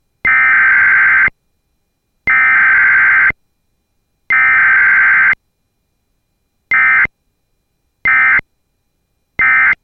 描述：门木重型后门，金属闩锁打开，关闭+报警系统beep.flac
标签： 开启 锁定 关闭 提示音 系统 背部 金属 报警器 门磁
声道立体声